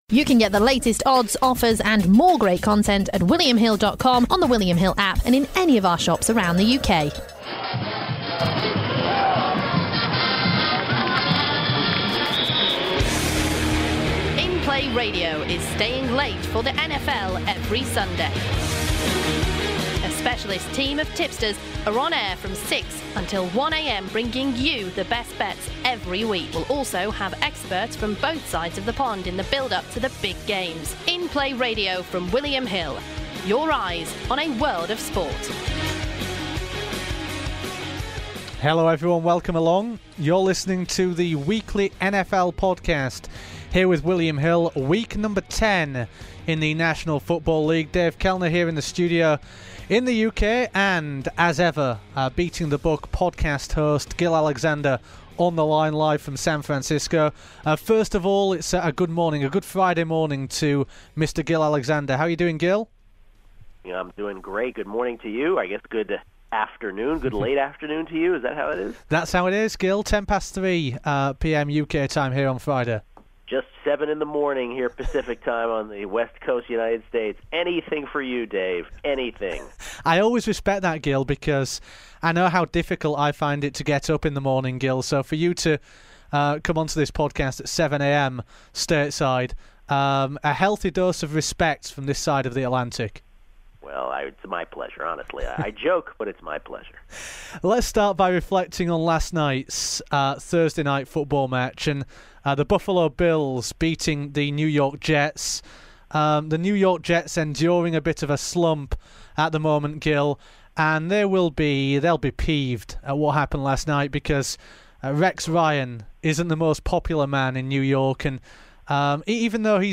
live from San Francisco